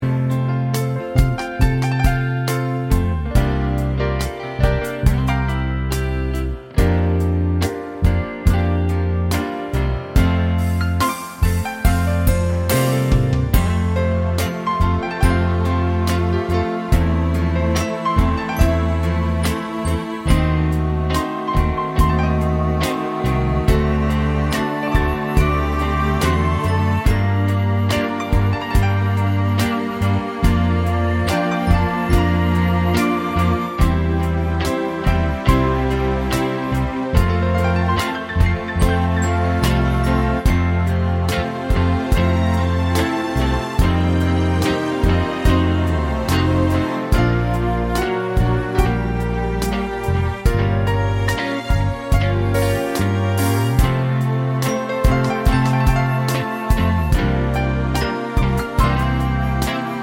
Live In Paris 1976 Pop (1970s) 4:36 Buy £1.50